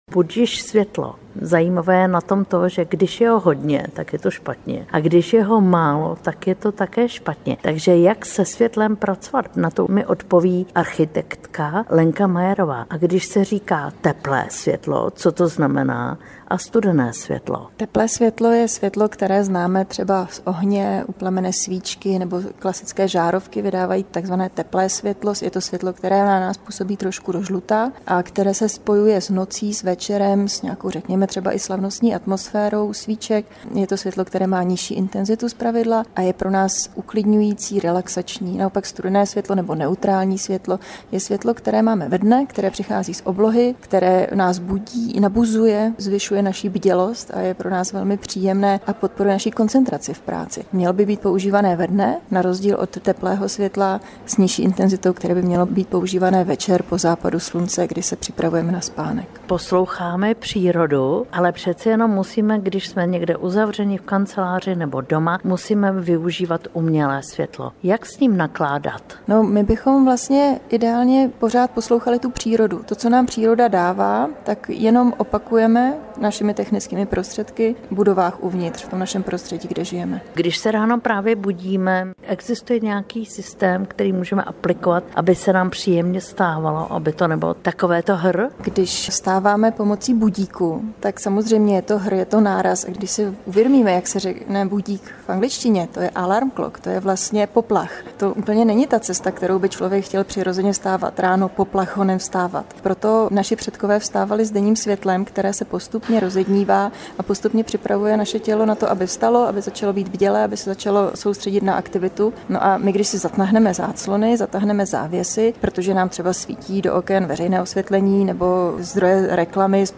AUDIO rozhovor: